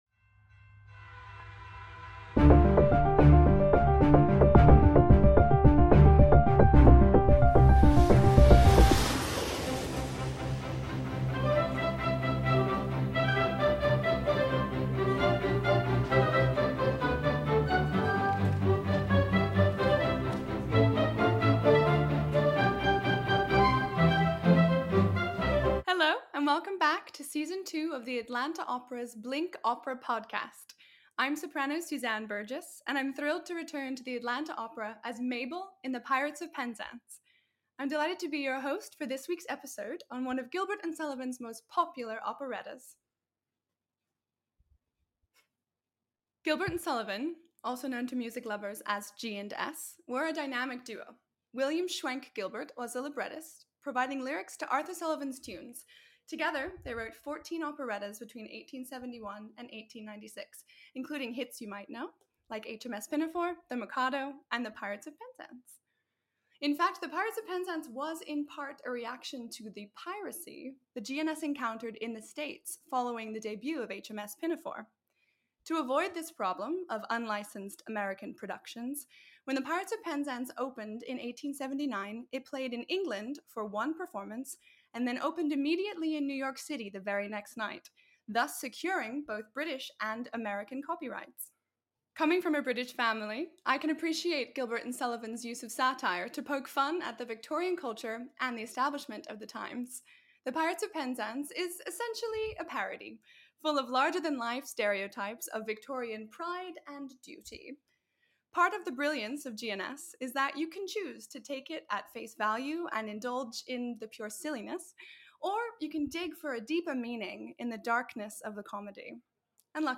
So, let’s listen to the highlights, unpack the plot, and hear about performance anecdotes in a time-efficient and fun way!